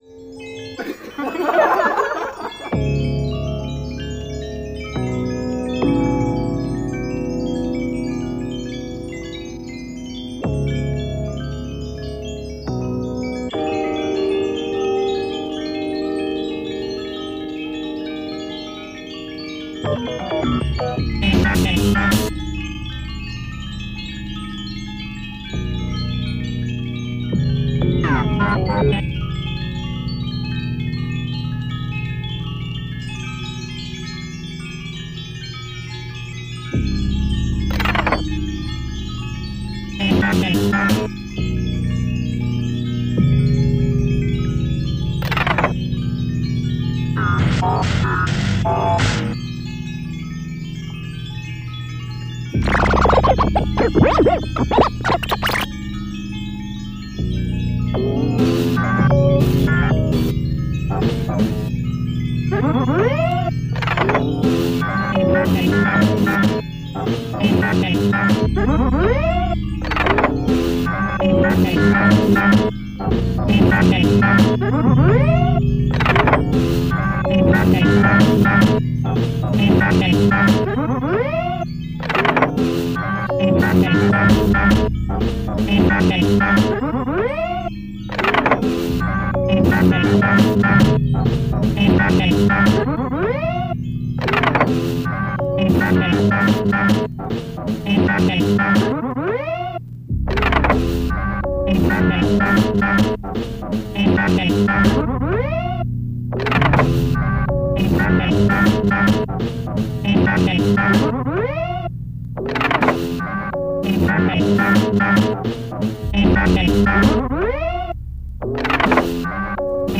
early tape music (1975-77)